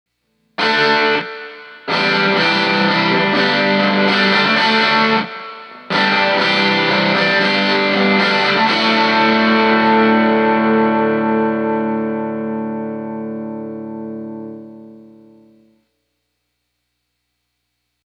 So, in this next clip (which again is the same track, but tweaked) I added some compression, reverb, a tiny bit of stereo delay and beefed up the lows with some Fat EQ: